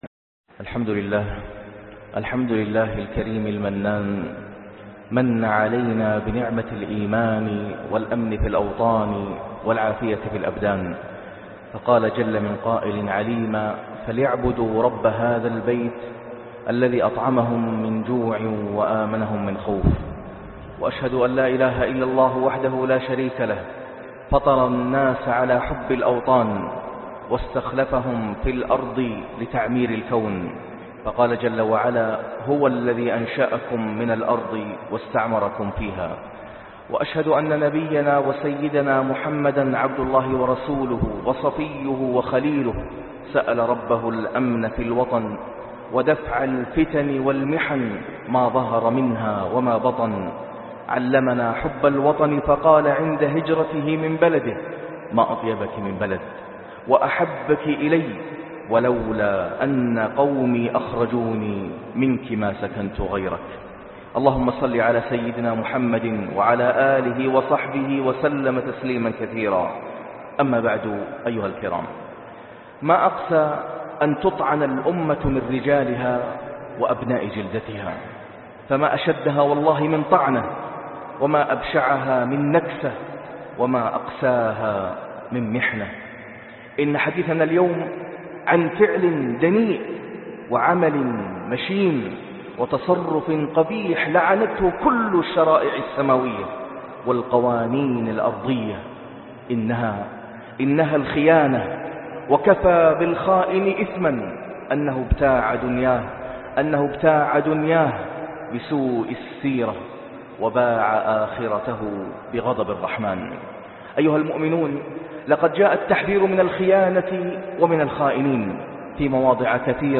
خيانة الأوطان - خطبة الجمعة